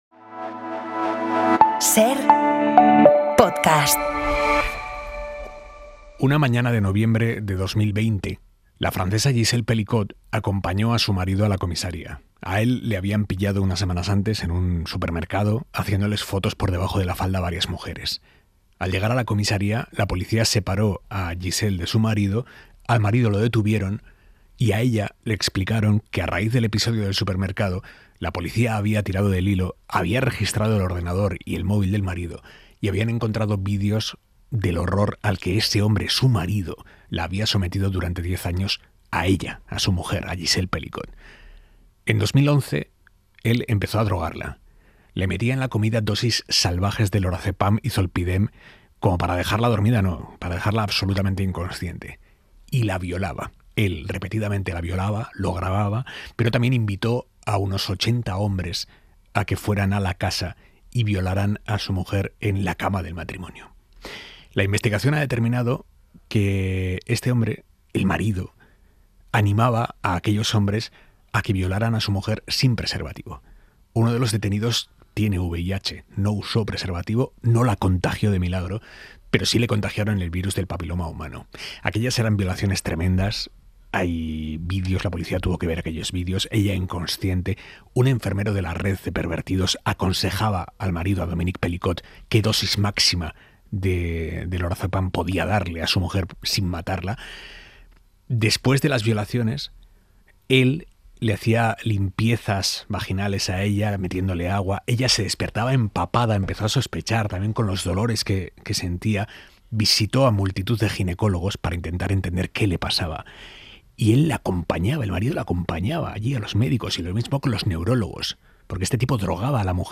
Aimar Bretos entrevista a Gisèle Pelicot, que acaba de publicar sus memorias escritas junto con la periodista Judith Perrignon: se trata de 'Un himno a la vida. Mi historia', publicado por la editorial Lumen. Durante casi una década, su exmarido la drogaba con somníferos para organizar violaciones orquestadas.